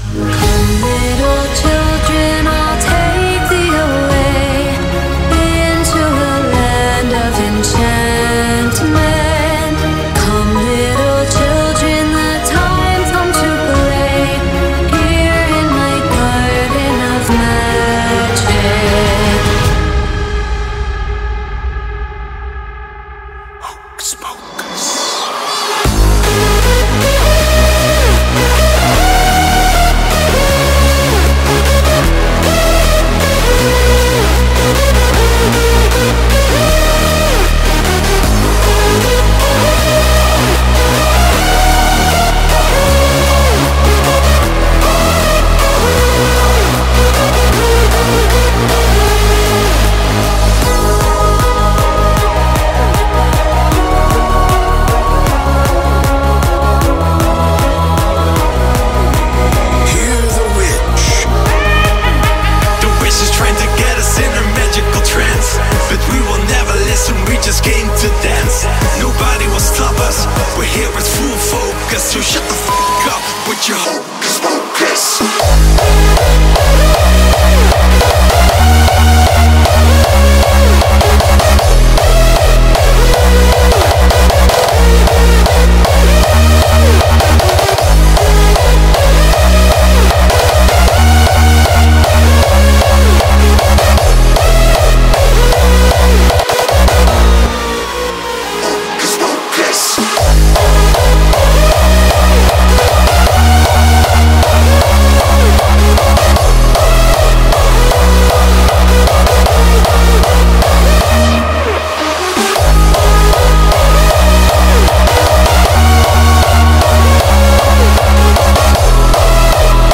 BPM51-155